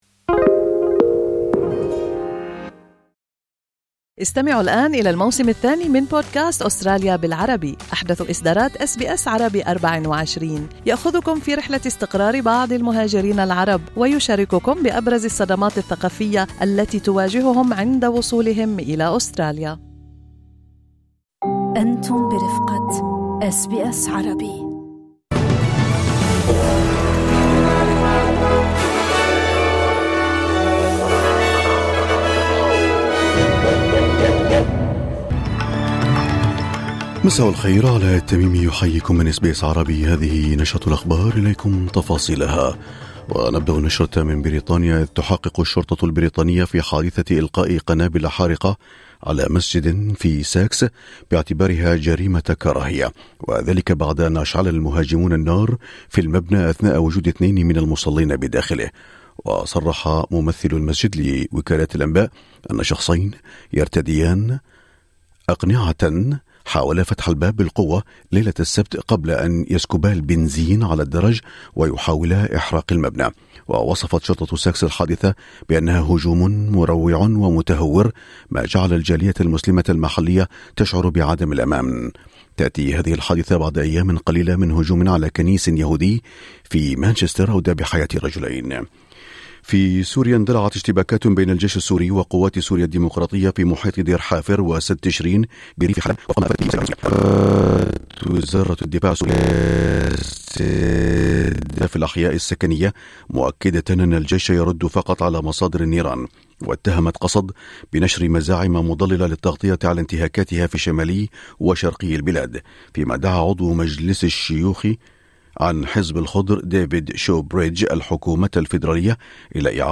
نشرة أخبار الظهيرة 06/10/2025